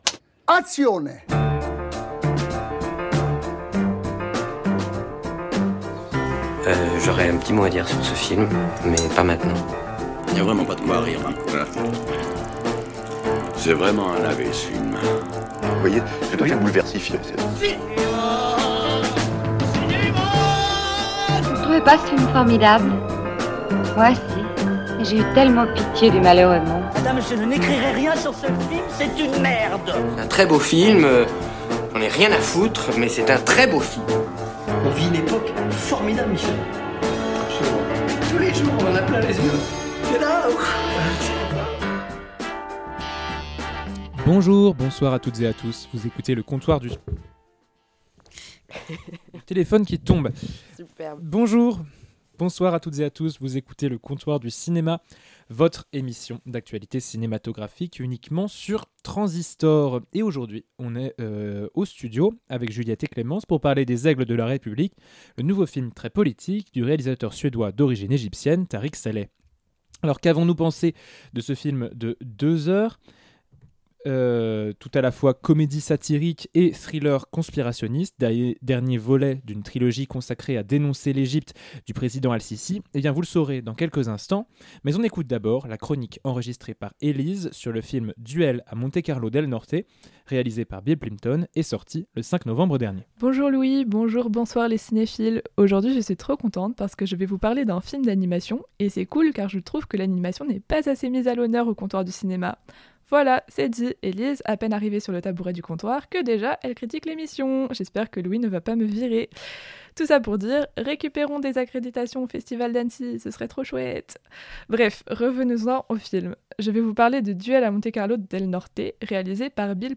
Chaque semaine, vos critiques se réunissent pour parler de films en salle, en toute subjectivité, pour vous convaincre – ou non – d’aller au cinéma cette semaine.
Émission enregistrée le 12 novembre 2025 (Duel) et le 24 novembre 2025 (Les Aigles de la République), au studio de Trensistor.